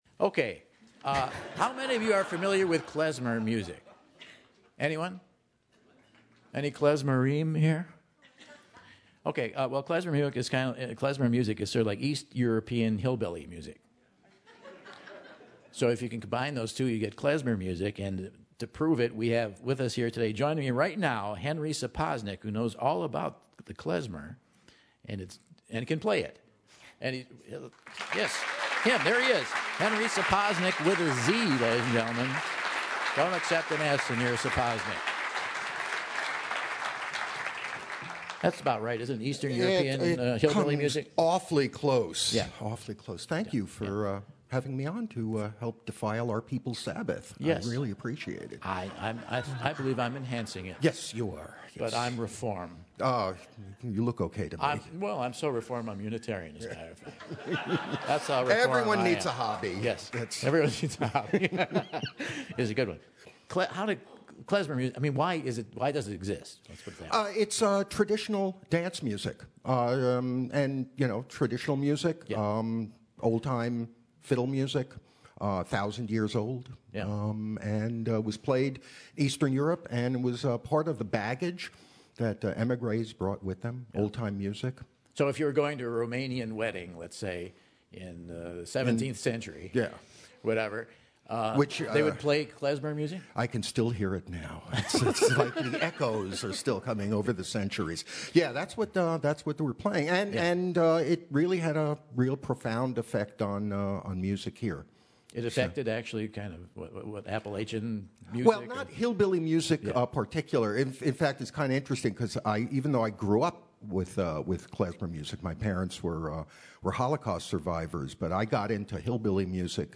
He hops on stage to showcase Klezmer music!